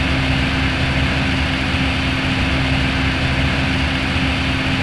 IDG-A32X/Sounds/SASA/CFM56/wingfwd/cfm-idle.wav at a097f45abd1d3736a5ff9784cdaaa96ad1f1bef2
Edit sounds to remove noise and make them loop better
cfm-idle.wav